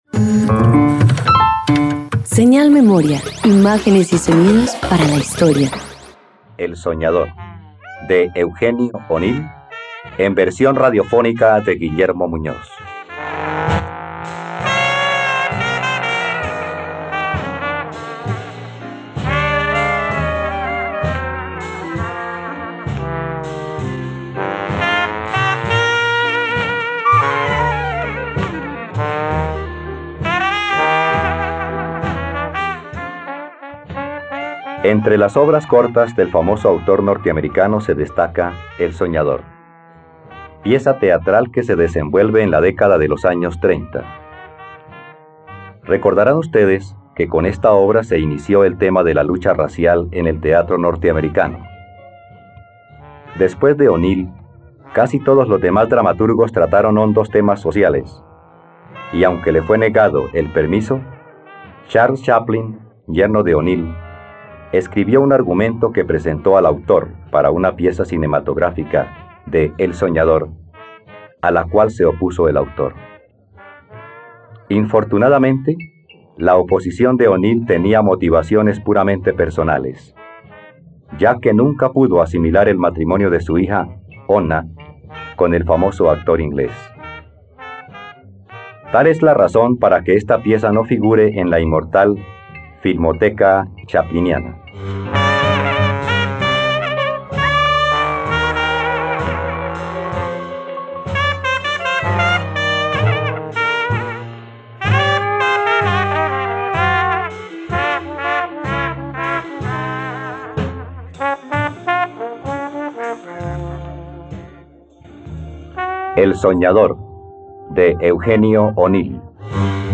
..Radioteatro. Escucha la adaptación radiofónica de “El soñador” de Eugene O'Neill por la plataforma streaming RTVCPlay.